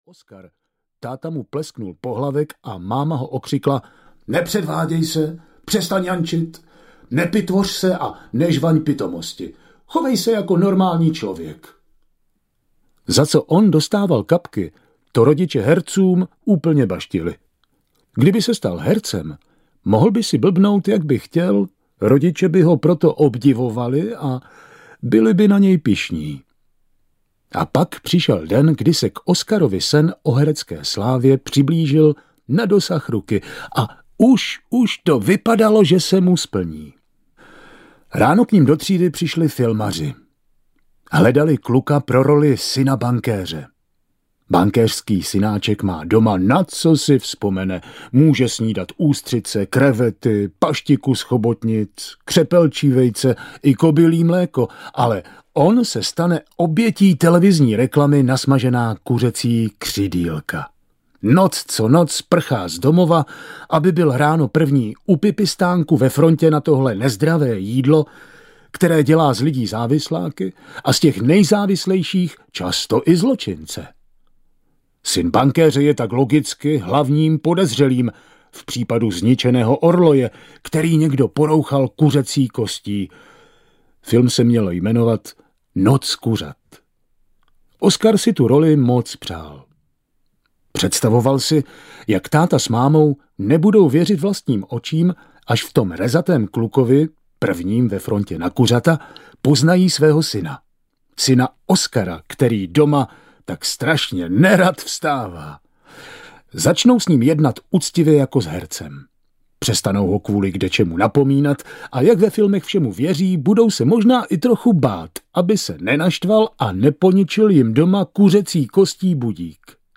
Inspektor Vogo audiokniha
Ukázka z knihy